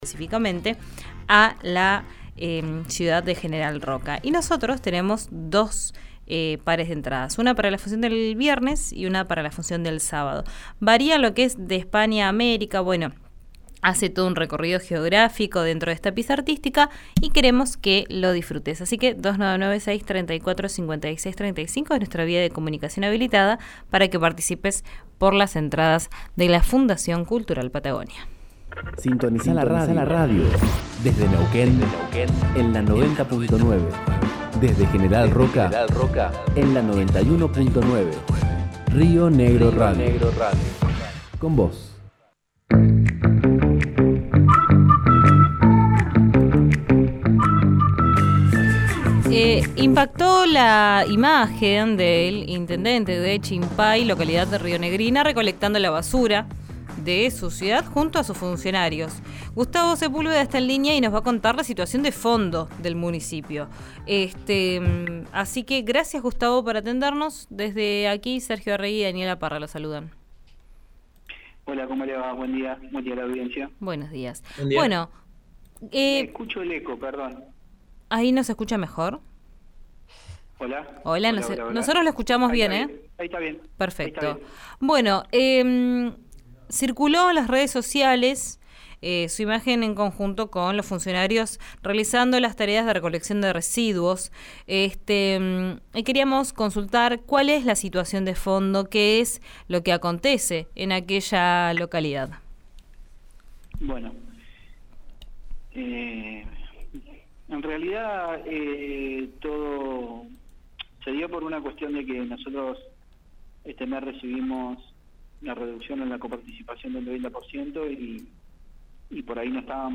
Escuchá a Gustavo Sepúlveda, intentende de Chimpay en RÍO NEGRO RADIO: